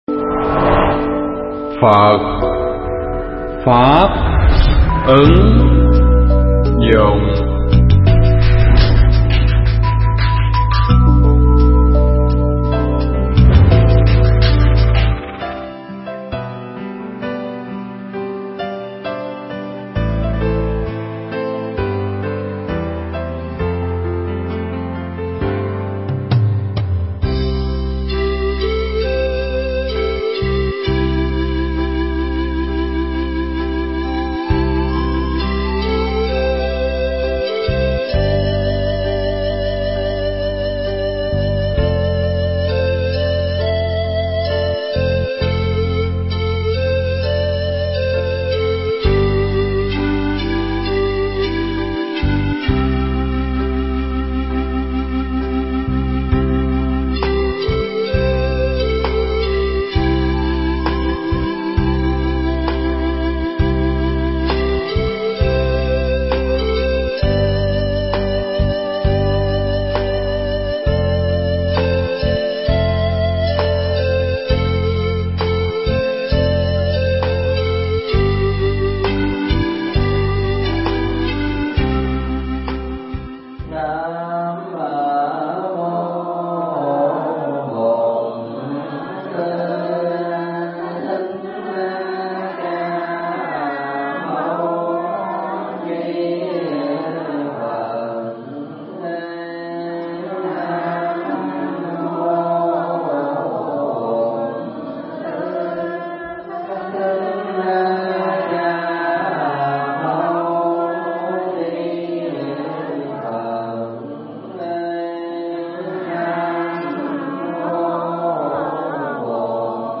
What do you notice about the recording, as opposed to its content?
thuyết giảng tại đạo tràng Viên Thông (Hoa Kỳ)